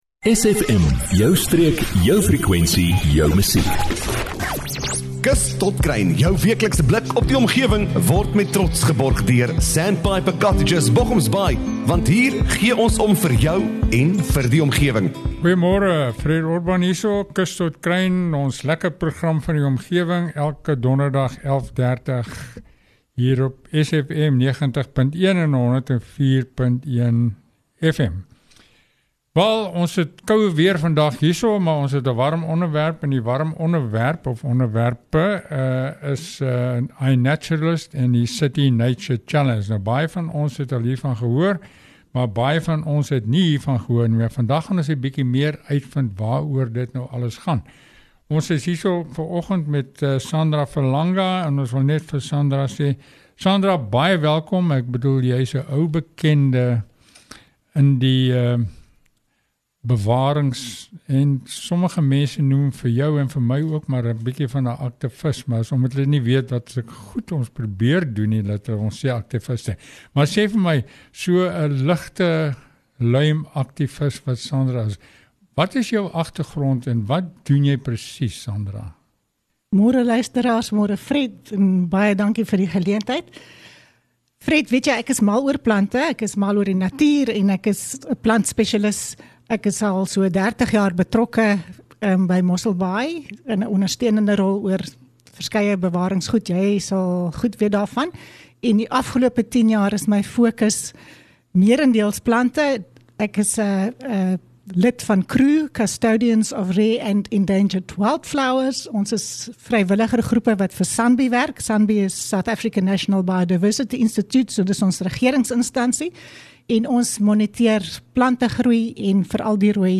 gesels oor die opkomende Intercity Challenge van Inaturalist. Hier kan elkeen van ons 'n Burger Wetenskaplike word. Elke persoon wat n selfoon of kamera het kry die geleentheid om jule bydrae te lewer vir die behoud van ons kosbare bio diversiteit op die Tuinroete.